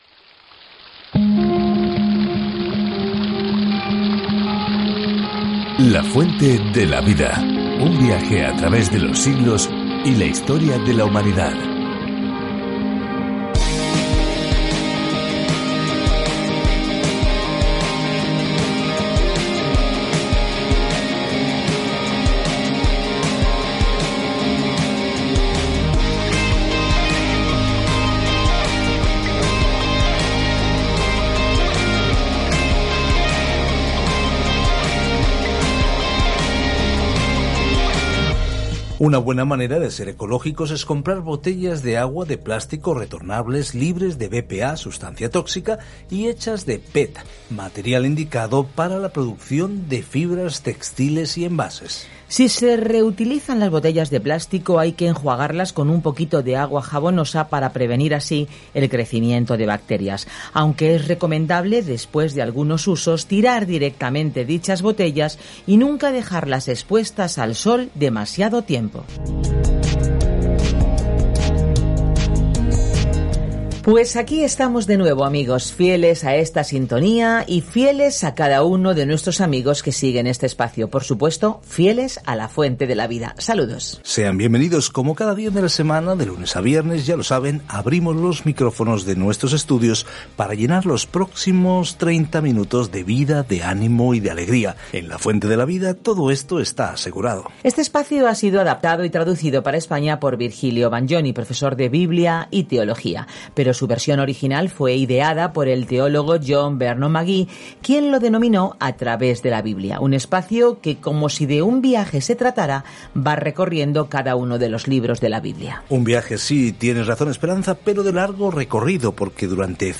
Escritura NÚMEROS 6:9-27 NÚMEROS 7:1-89 Día 6 Iniciar plan Día 8 Acerca de este Plan En el libro de Números, caminamos, vagamos y adoramos con Israel durante los 40 años en el desierto. Viaja diariamente a través de Números mientras escuchas el estudio en audio y lees versículos seleccionados de la palabra de Dios.